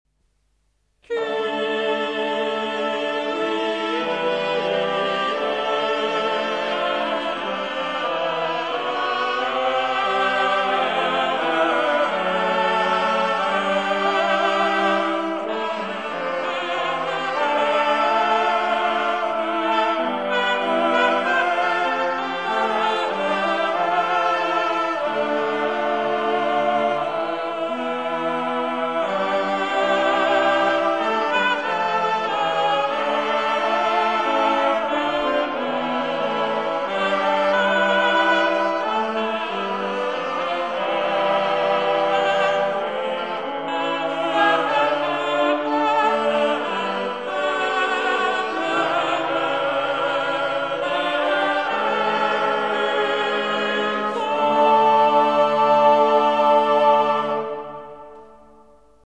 (1300-1377) Het is een vierstemmige zetting van het ordinarium.
• de vierstemmige zetting (in die tijd ongebruikelijk)